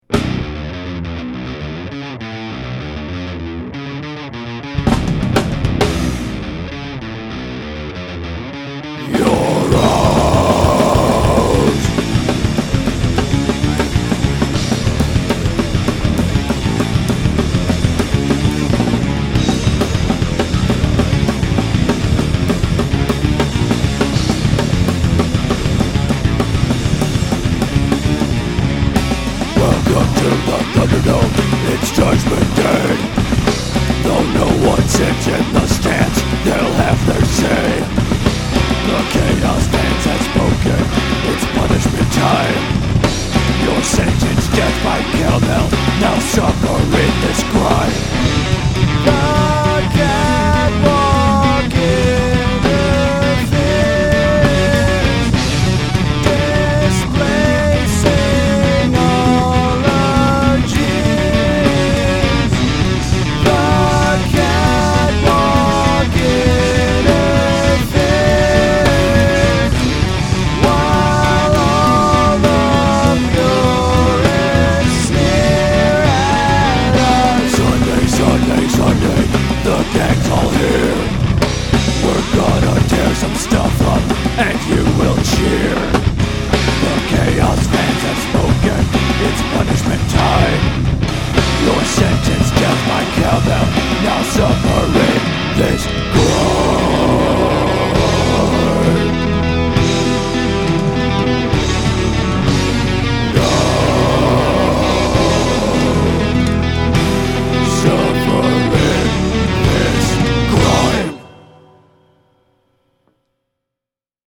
This was a taxing track to record, particularly on the drums (I hadn’t written anything calling for blast beats until recently), but the end result is totally worth the trouble. The “synth pad” buried in the mix at the end is no pad – that’s three tracks of me singing harmonies in falsetto.
Throw in some evil sounding words – grime, suffer, judgement, punishment – and you got yourself some death metal!